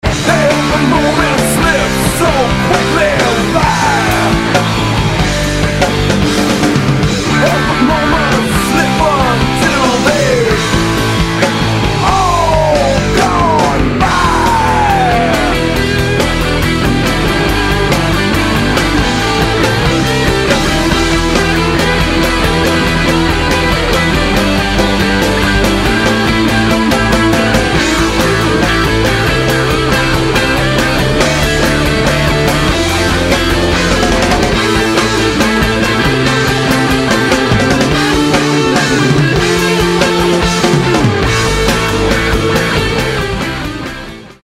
BASS & VOCALS
GUITAR
DRUMS